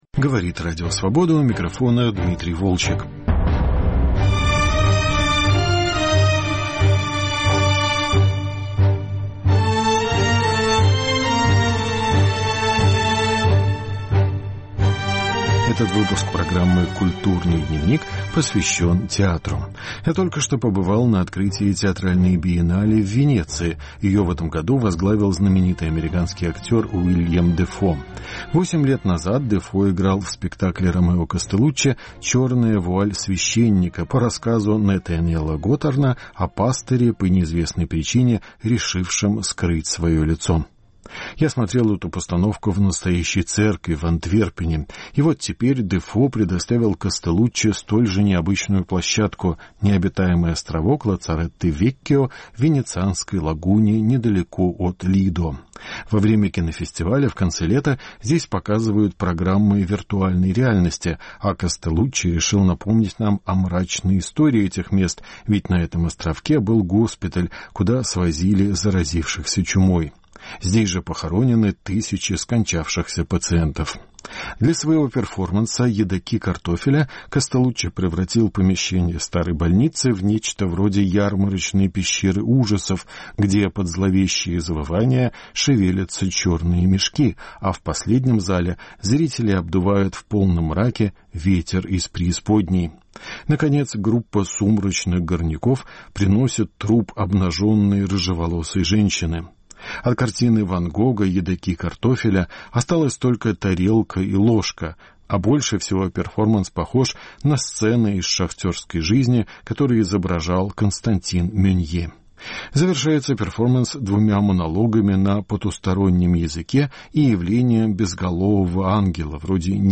Разговор с московским режиссером, поставившим спектакль на Бродвее